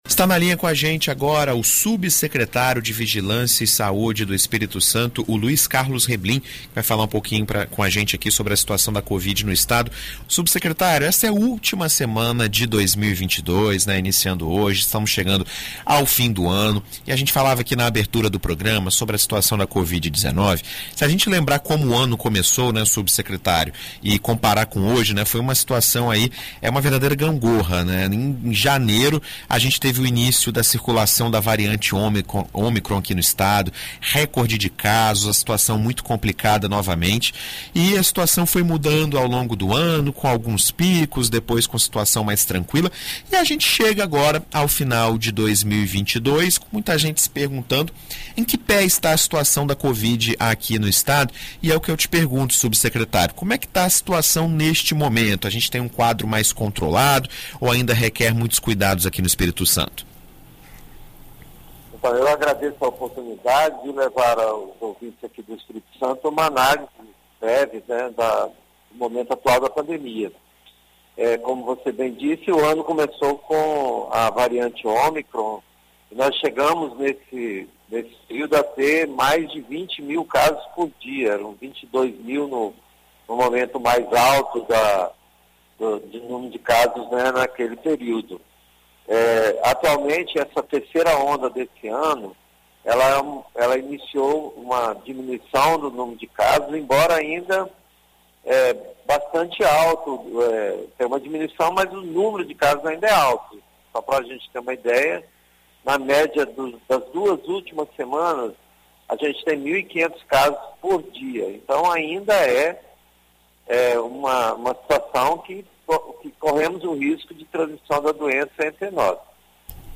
Em entrevista à BandNews FM Espírito Santo nesta segunda-feira (26), o subsecretário de Estado de Vigilância em Saúde, Luiz Carlos Reblin, fala sobre o panorama do coronavírus no Espírito Santo durante este final de ano e reforça as orientações para evitar uma nova escalada da doença em território capixaba.